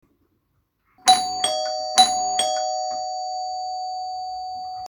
Download Doorbell sound effect for free.
Doorbell